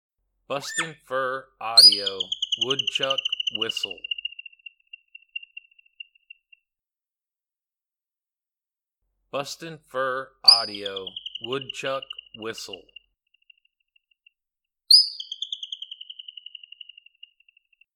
BFA Woodchuck Whistle
Juvenile Ground Hog whistling.
BFA Woodchuck Whistle Sample.mp3